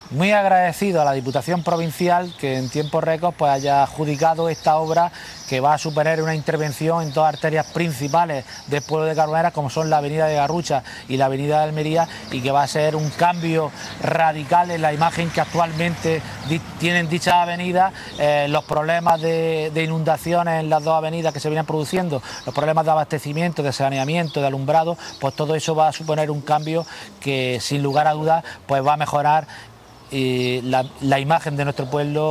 02-06_carboneras_obras_alcalde.mp3